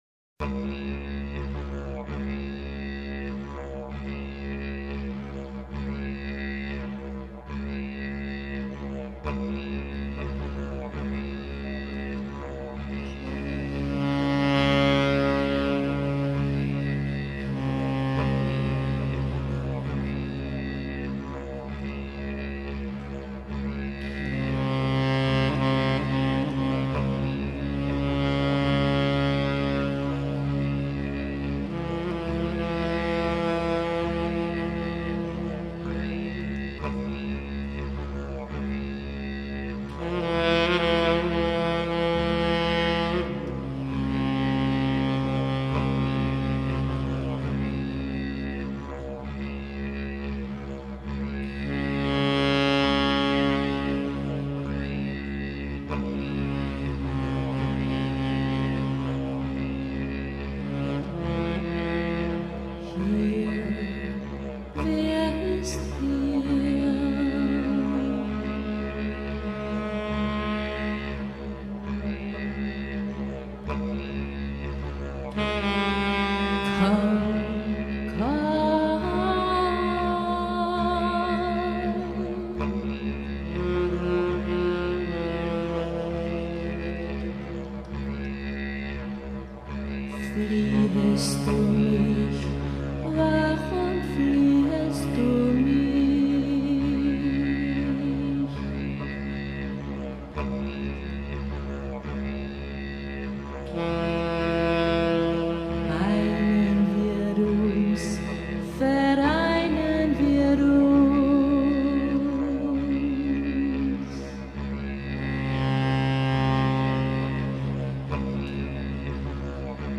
VOCALS, SAXOPHONES, VACUUM CLEANER PIPE